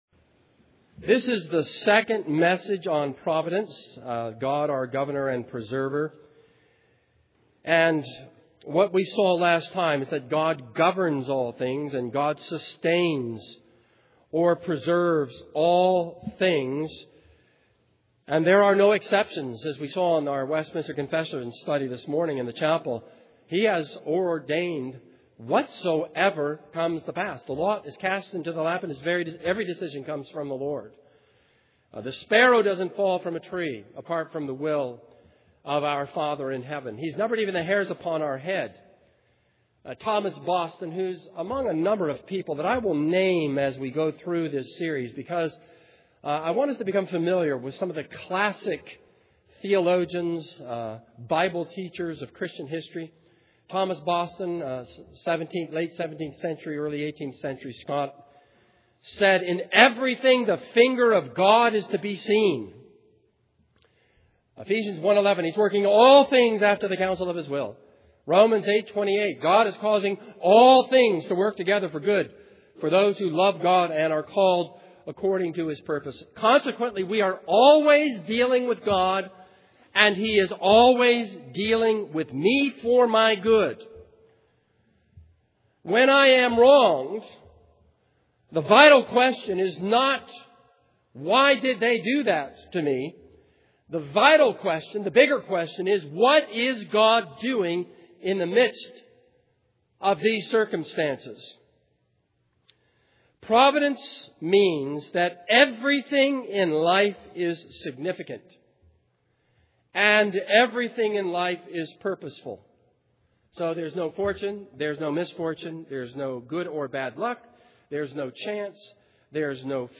This is a sermon on Luke 12:22-28.